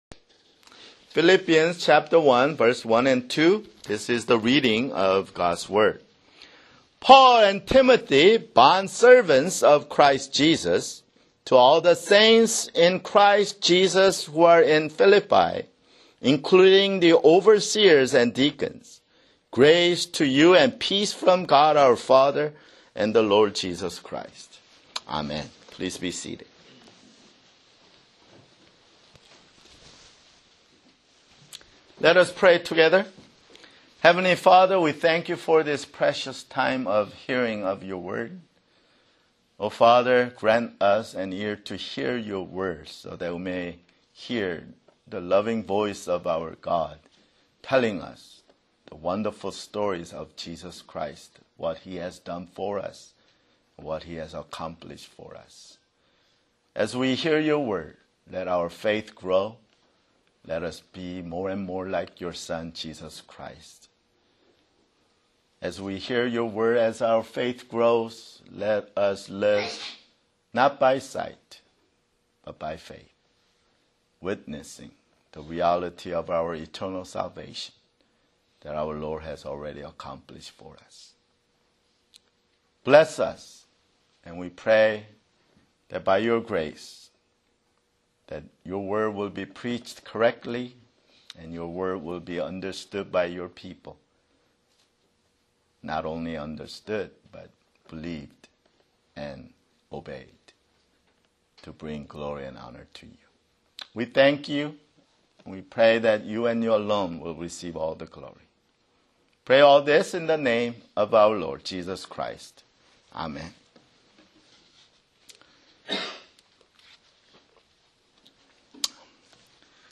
Christ and Heaven OPC: [Sermon] Philippians (4)